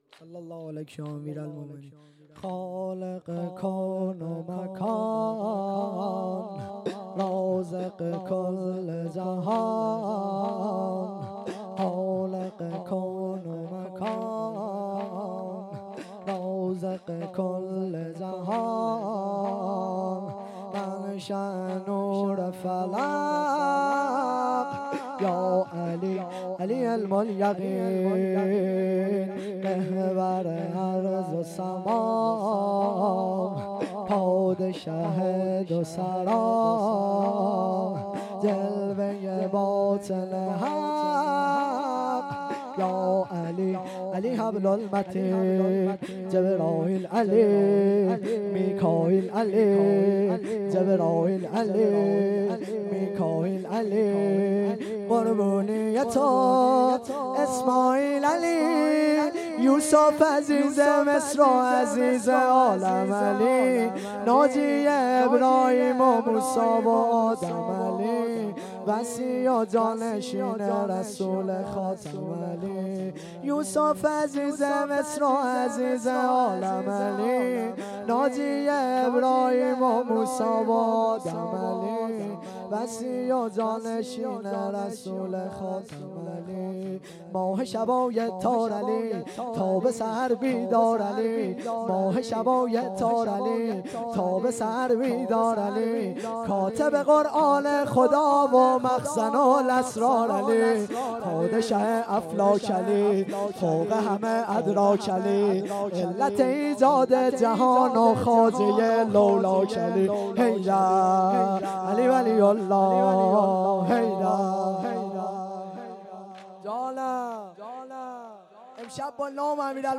خیمه گاه - جلسه ی زیارت عاشورای حسینی(,ع) - واحد شلاقی(شهادت حضرت رباب سلام الله علیها)